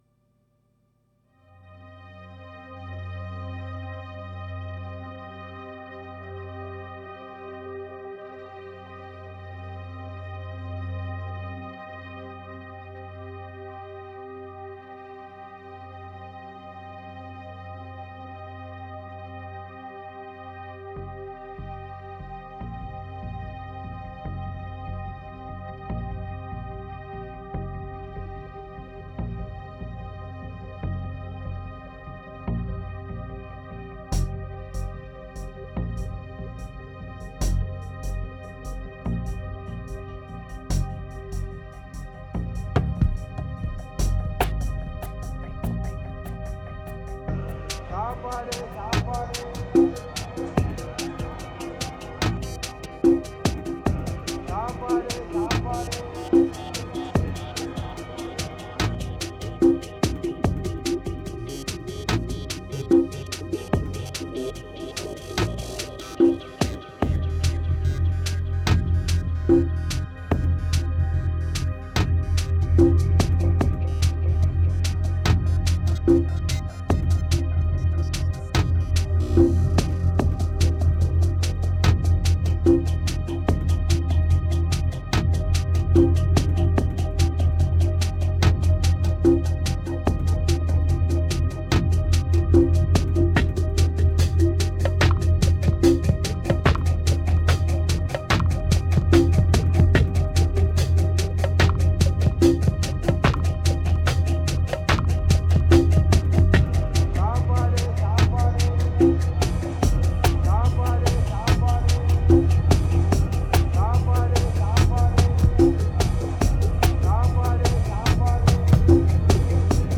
2679📈 - 67%🤔 - 73BPM🔊 - 2011-01-07📅 - 232🌟